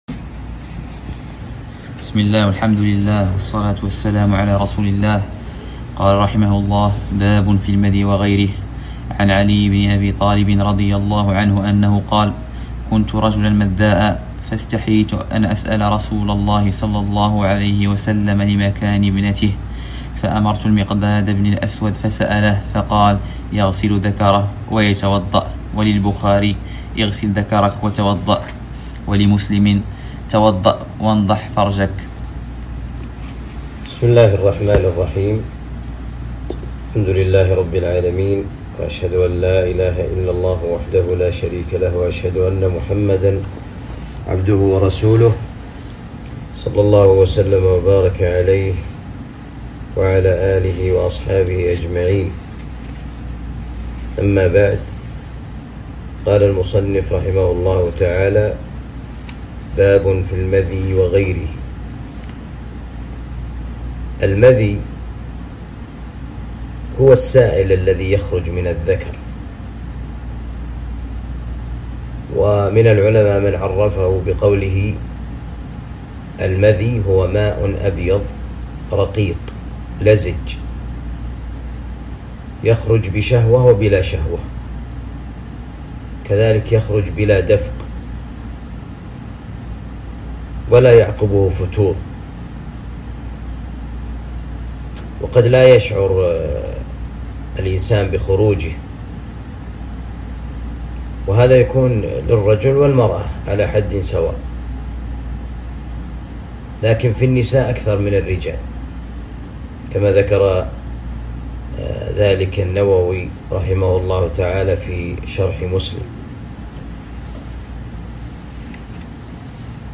الدرس السابع شرح عمدة الأحكام